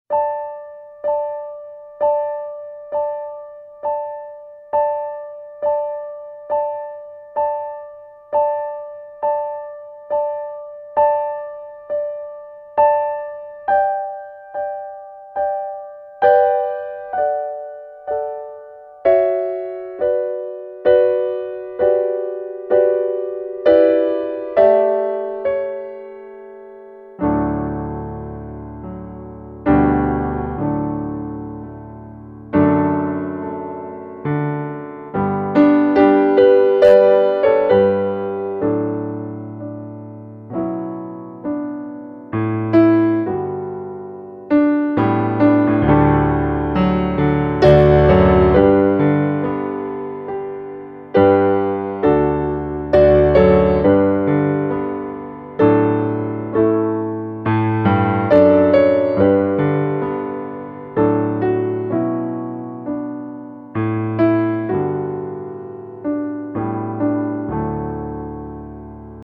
Low Key: